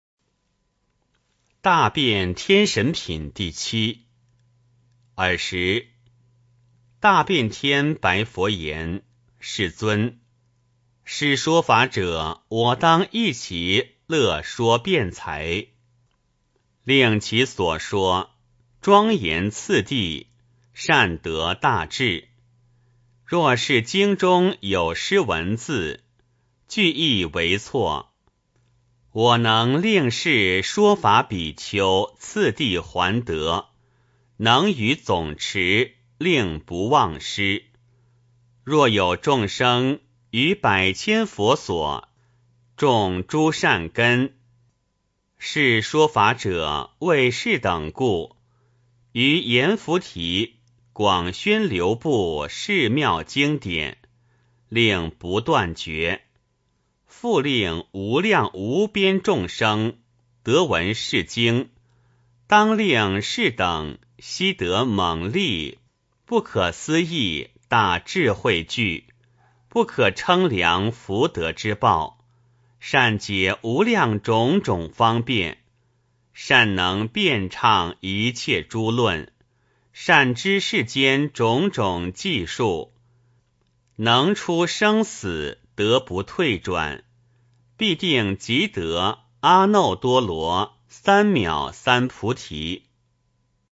金光明经.大辩天神品第七 - 诵经 - 云佛论坛